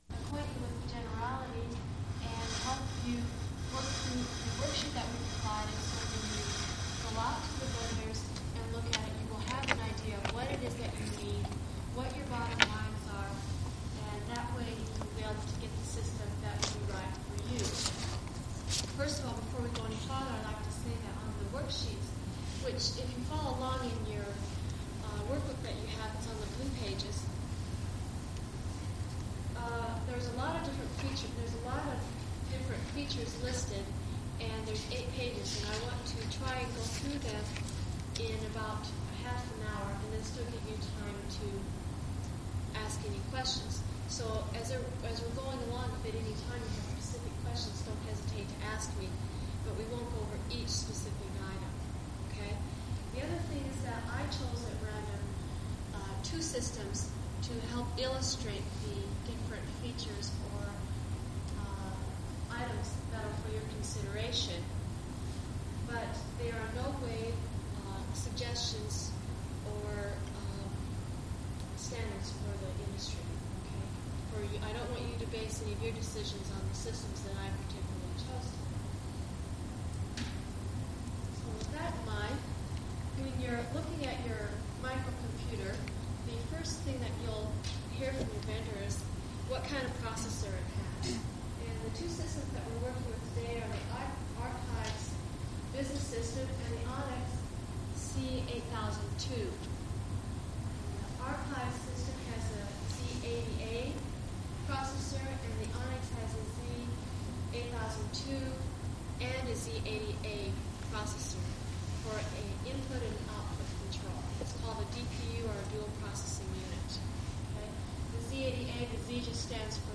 Computer Lecture @ UNL - March 11th, 1983 - Download
COMPUTER LECTURE UNL - 11 MAR 83
The two D90 tapes have been condensed into one file, with each of the four sides seperated by a 440Hz tone.
The D60 tape does not appear to contain anything on Side B. The D60 tape is a seperate file because it seems to be from the same conference or whatever, but it's clearly a different lecture at a different time.